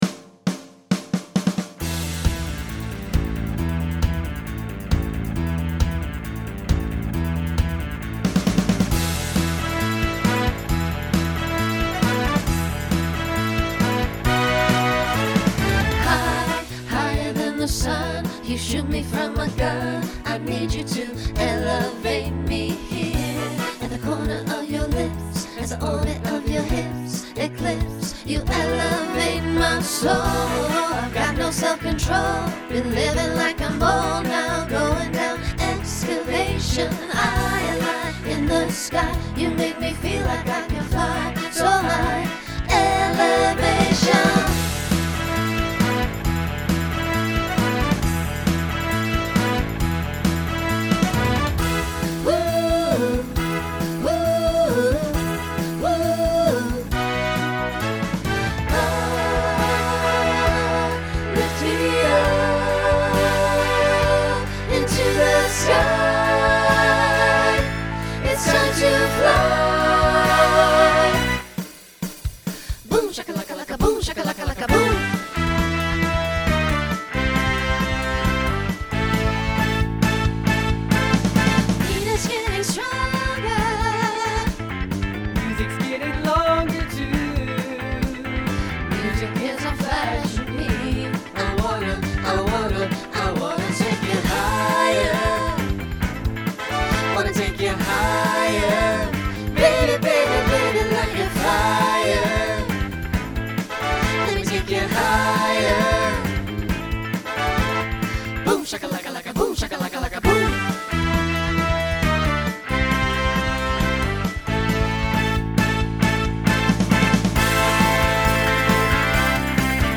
Genre Rock Instrumental combo
Voicing SAB